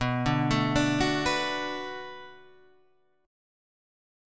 B Minor 7th